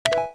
Index of /phonetones/unzipped/LG/KM555e-Clubby/Keytone sounds/Bee-beep